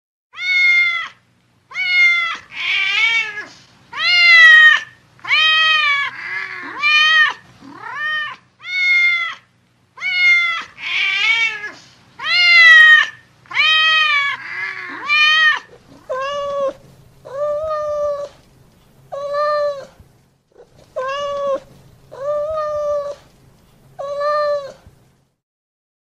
Громкий лай большой сторожевой собаки